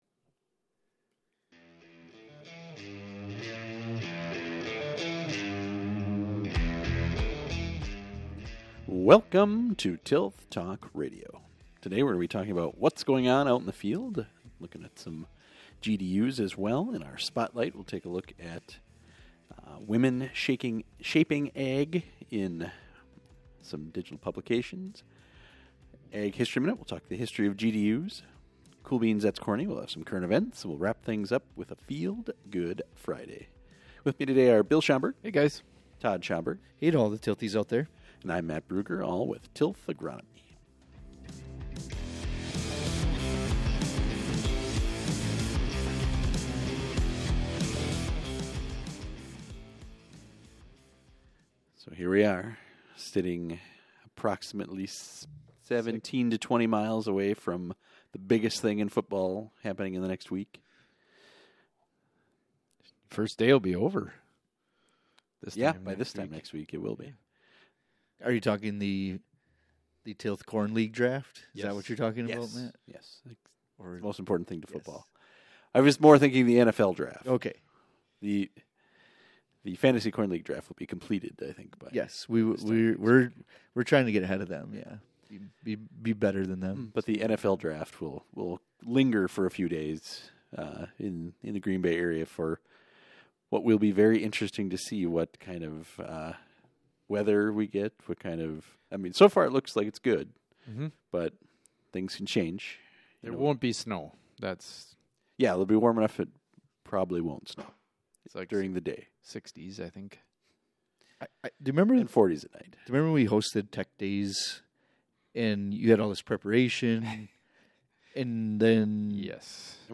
Special Interview Episode